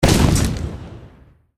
academic_skill_airshot_a.ogg